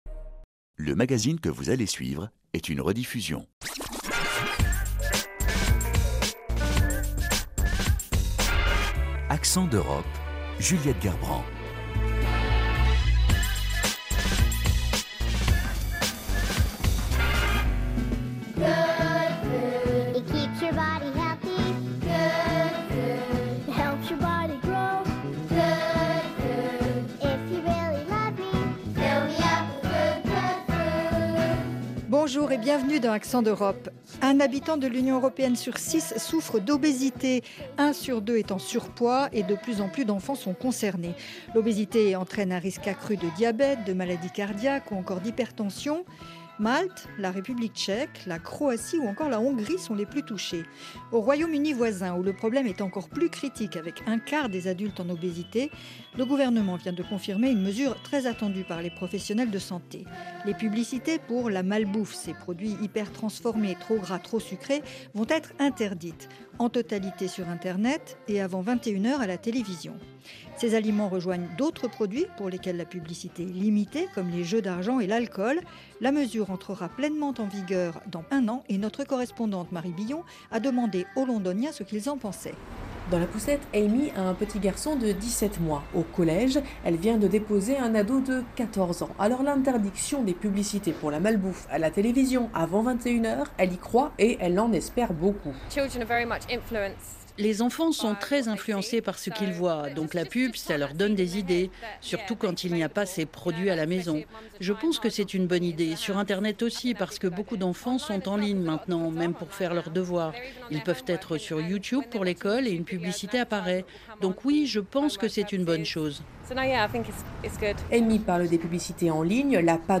Avec ce reportage, découvrez un atelier thérapeutique à Amiens dédié aux patients souffrant d’obésité. L’expérimentation GPS Obésité y est présentée comme un dispositif innovant pour améliorer le parcours de soin.